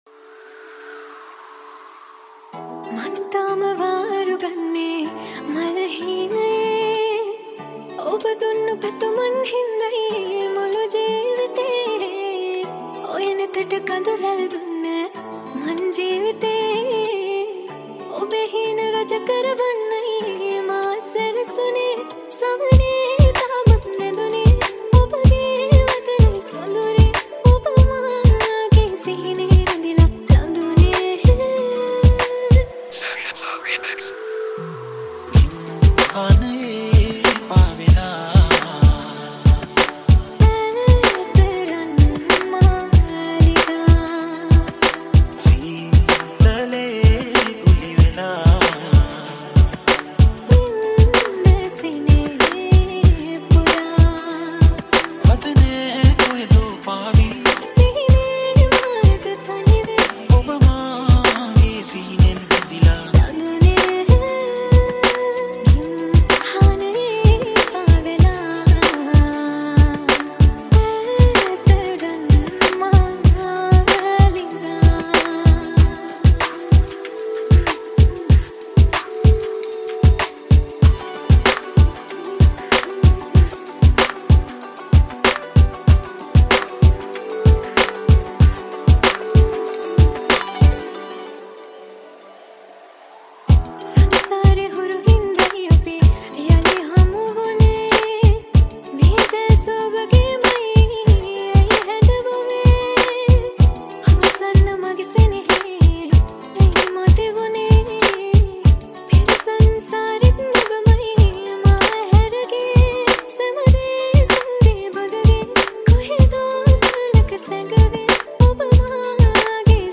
High quality Sri Lankan remix MP3 (3).
remix